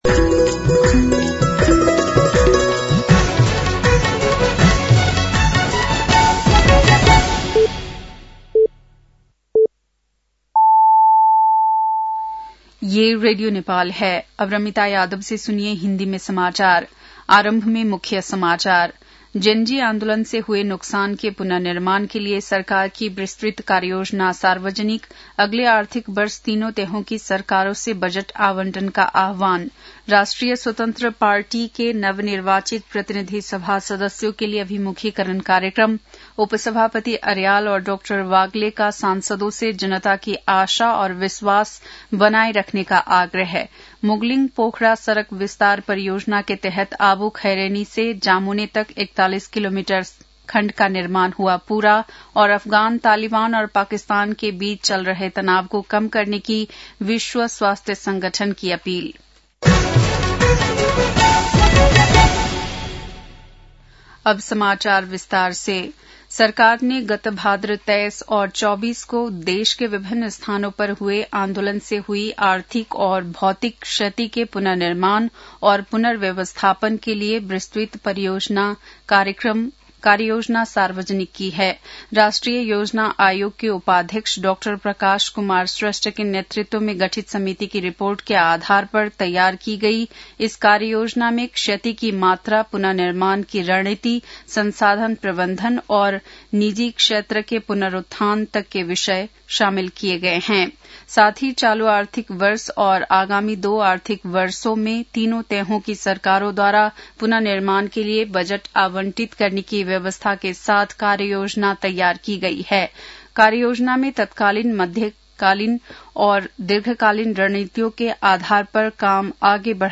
बेलुकी १० बजेको हिन्दी समाचार : ३ चैत , २०८२